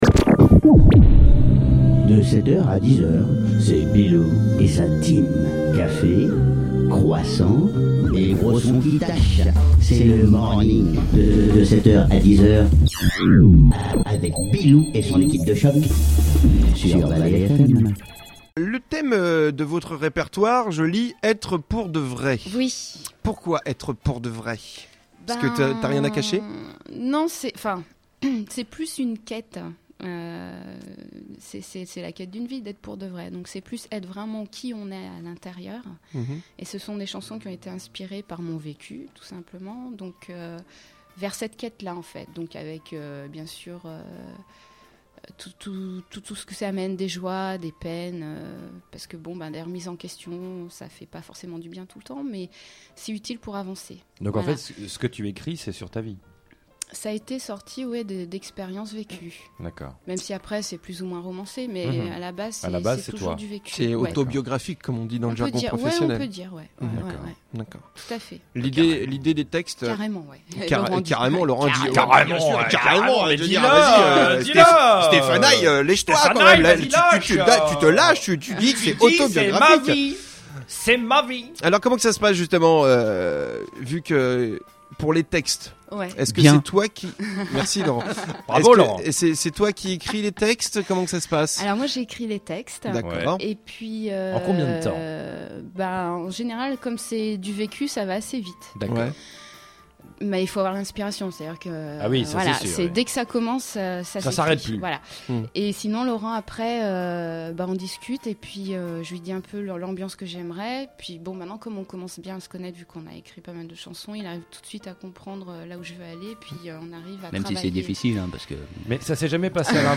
à travers ses compositions POP / ROCK